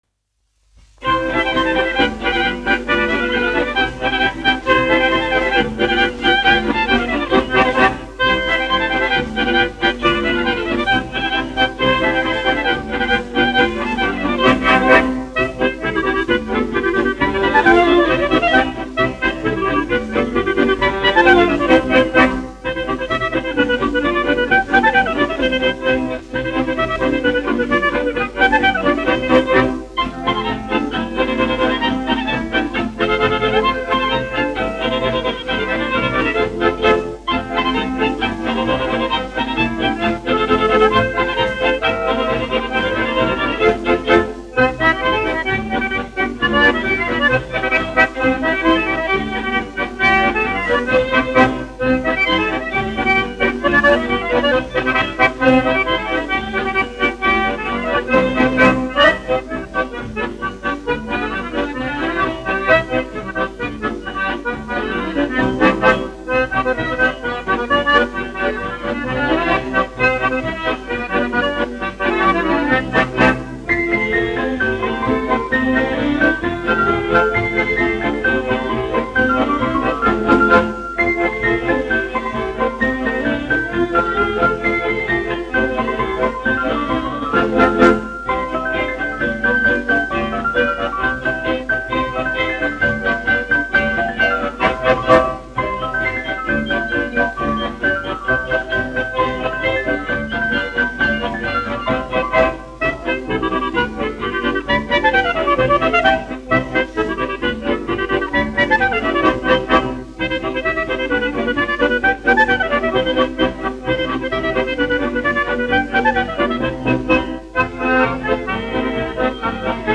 1 skpl. : analogs, 78 apgr/min, mono ; 25 cm
Polkas
Skaņuplate